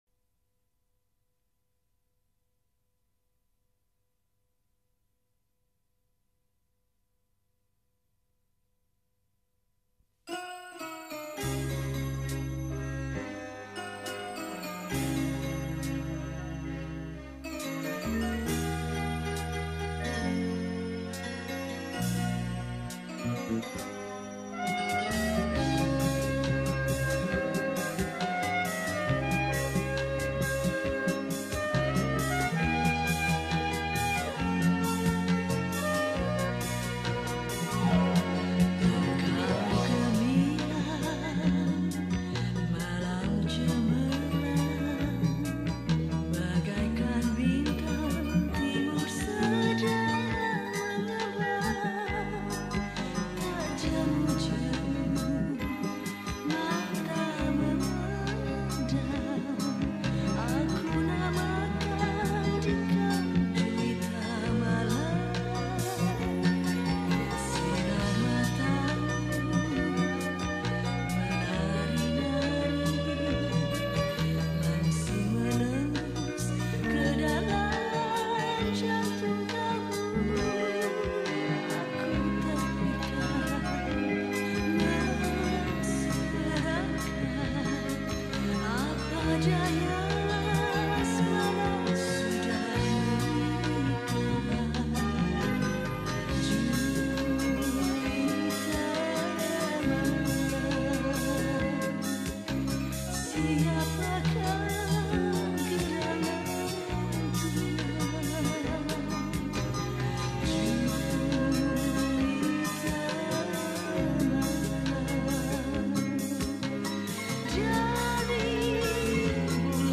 penyanyi pop jawa